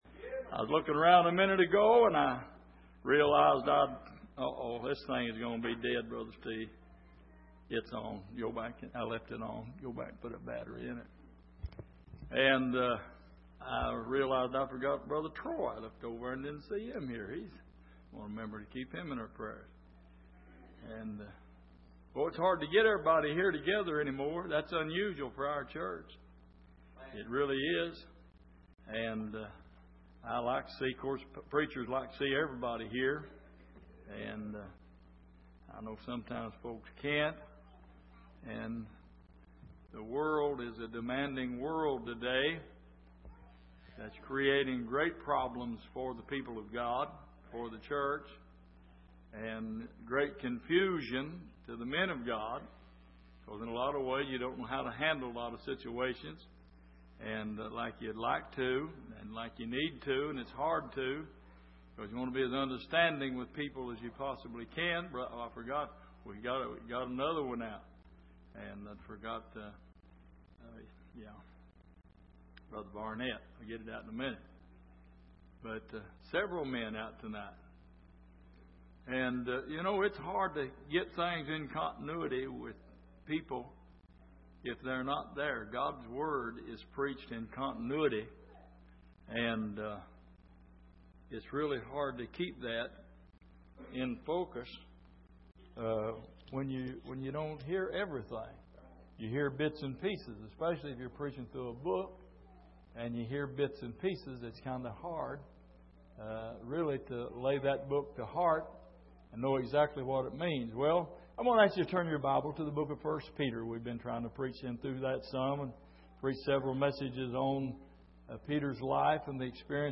Passage: 1 Peter 1:13-16 Service: Midweek